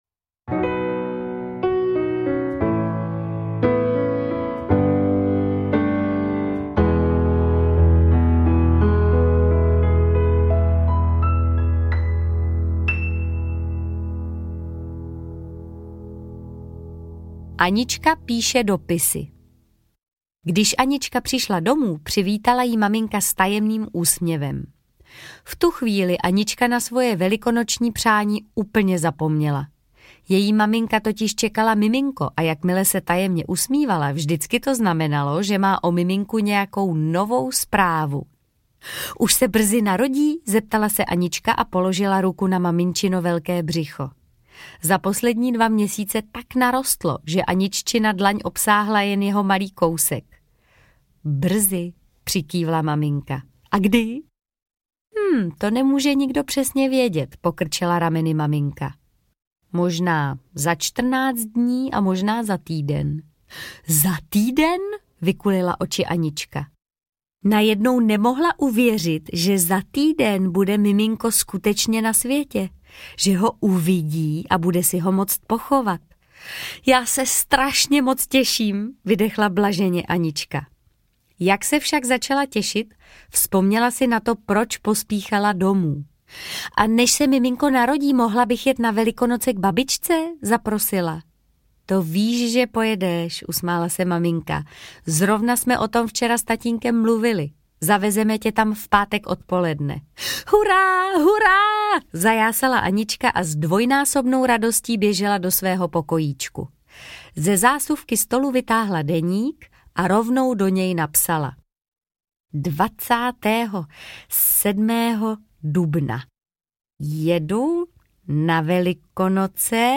Audiobook
Read: Martha Issová